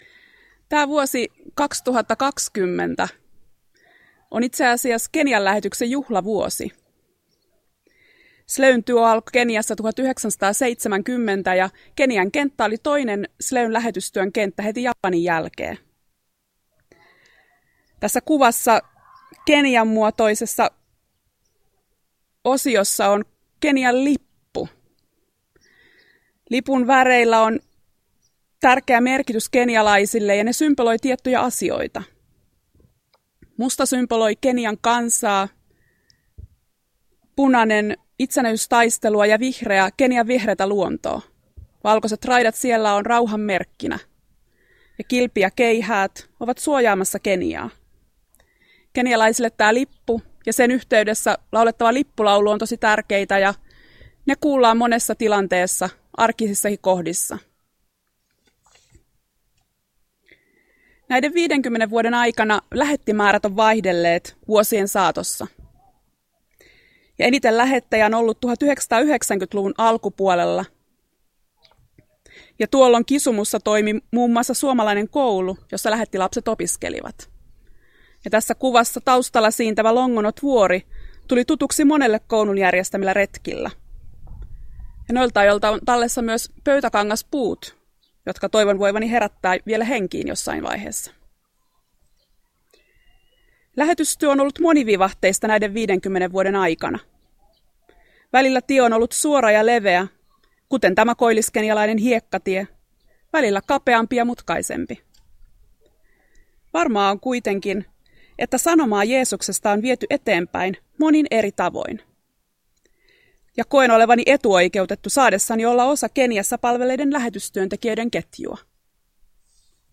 Esantuvan juhannusjuhlassa